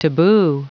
L'accent tombe sur la dernière syllabe: